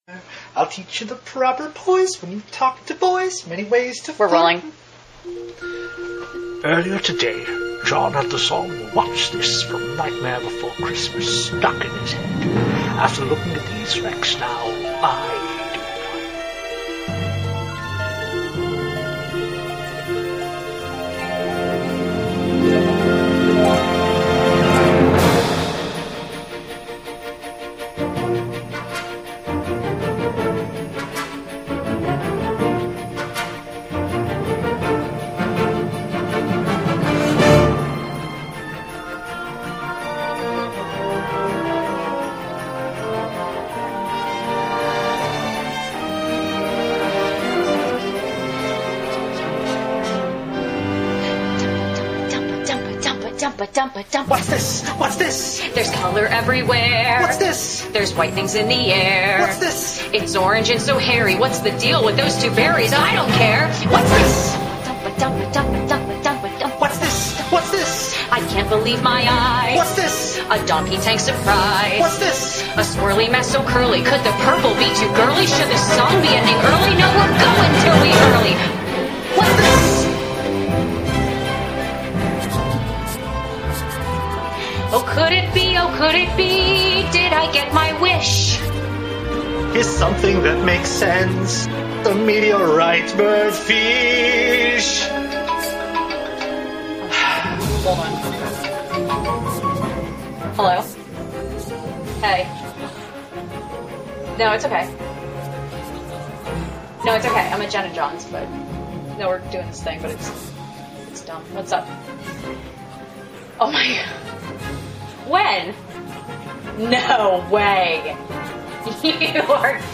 A Parody: